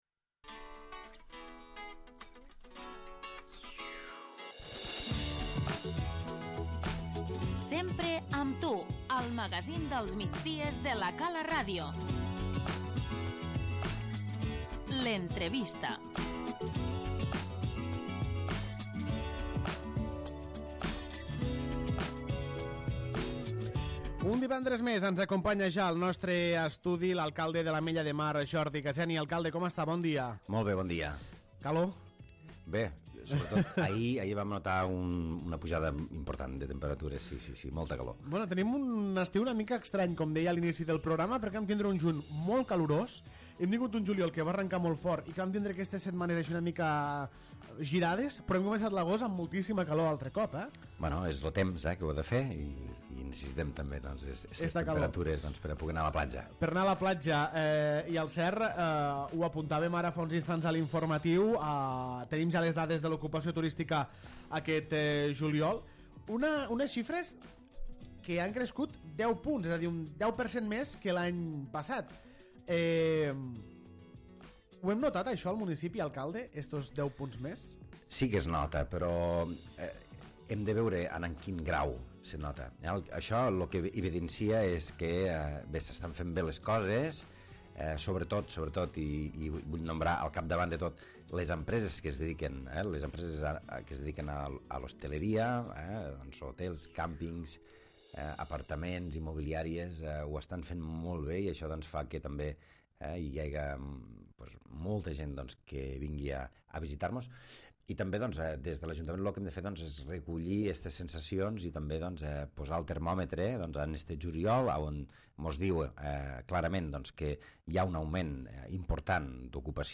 L'entrevista - Jordi Gaseni, alcalde de l'Ametlla de Mar